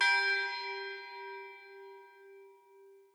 bell1_4.ogg